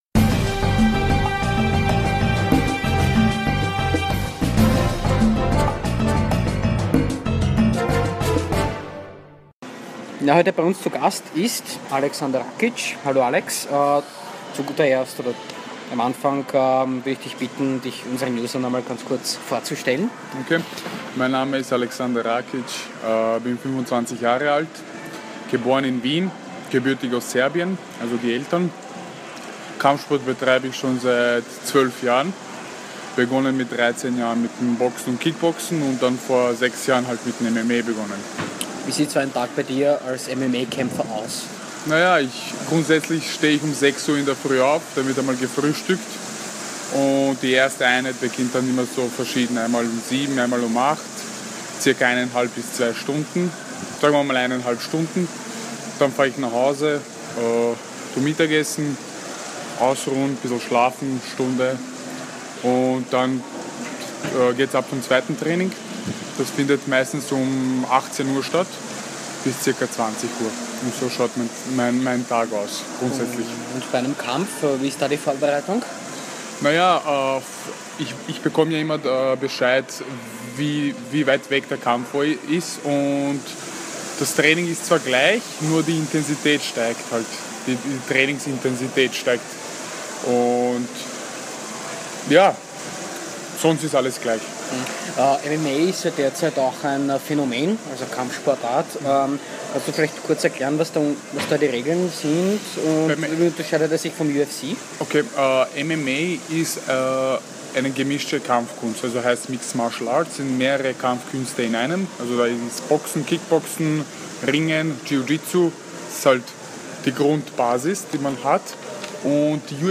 Interview mit MMA-Kämpfer Aleksandar Rakic